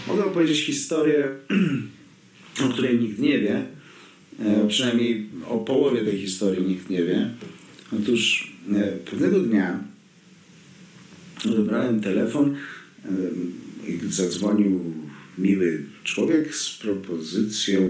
• Cogito's voice claim is Maciej Stuhr[21].
Voice Claim (edited)